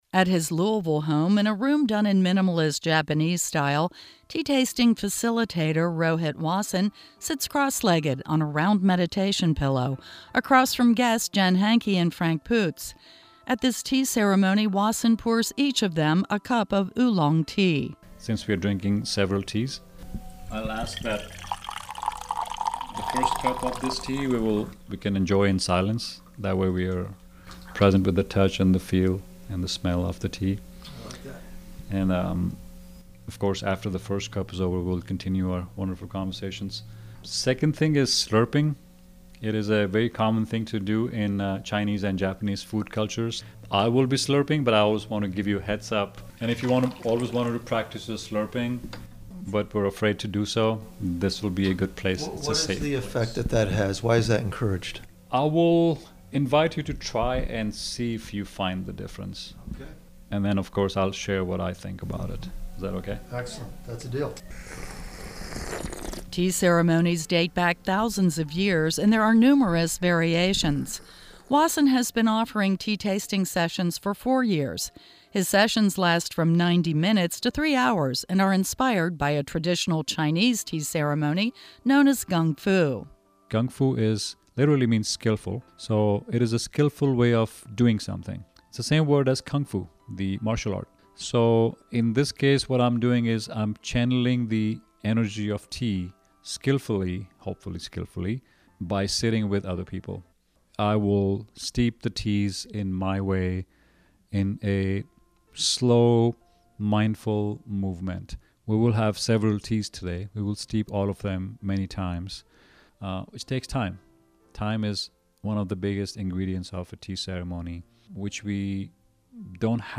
The radio story